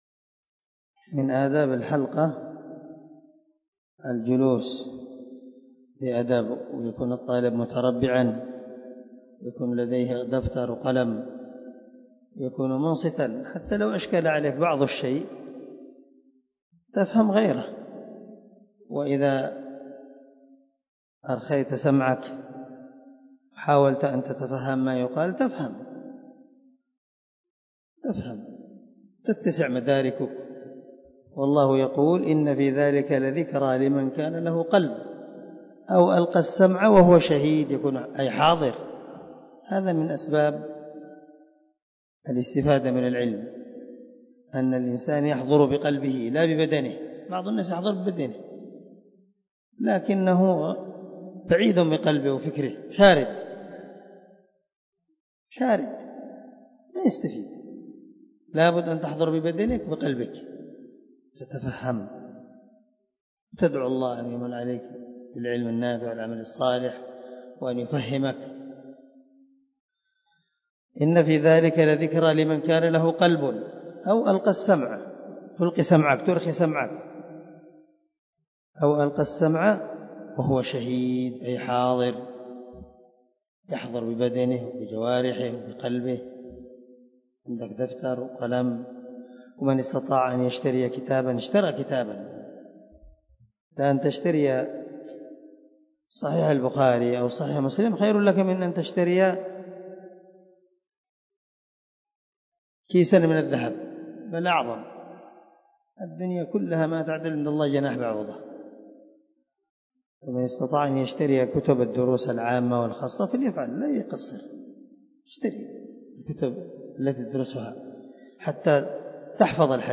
مقتطف من درس مسلم بيان بعض آداب حلق العلم
مقتطف-من-درس-مسلم-بيان-بعض-آداب-حلق-العلم.mp3